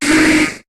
Cri de Scorplane dans Pokémon HOME.